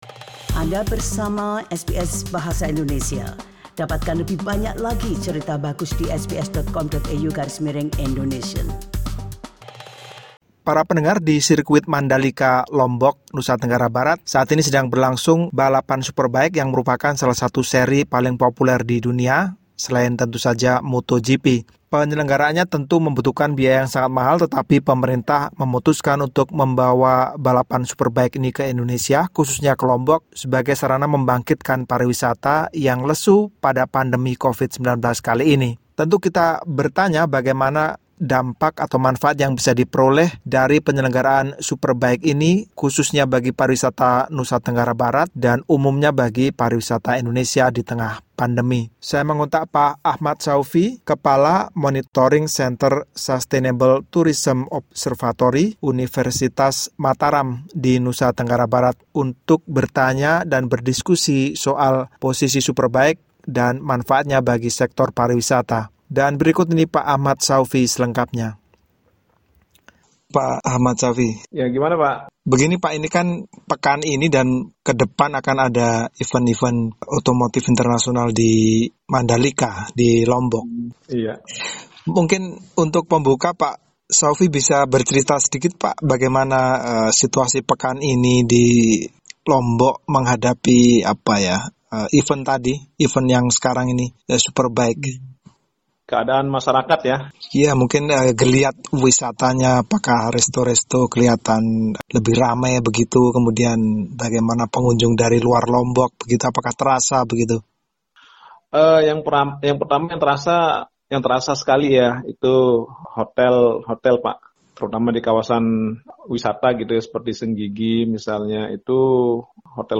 SBS Indonesian